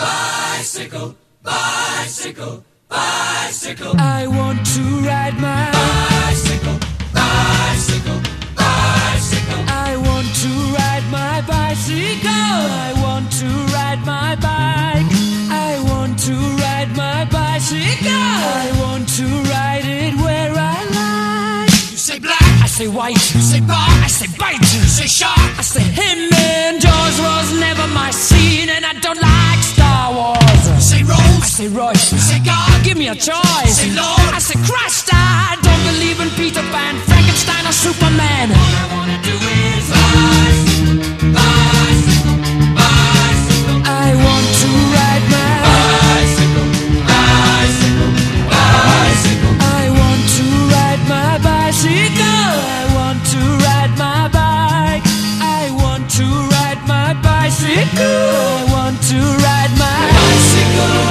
ROCK / 70'S